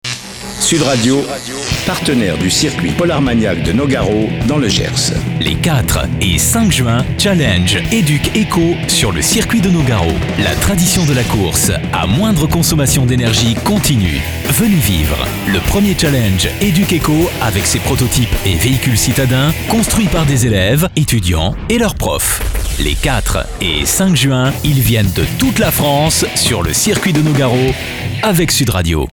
bande annonce sud radio
pub_nogaro_educ_eco2.mp3